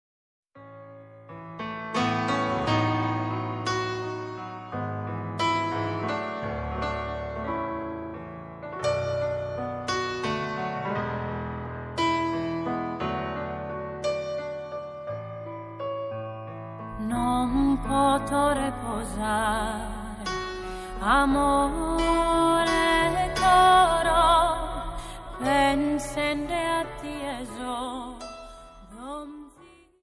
Dance: Waltz 29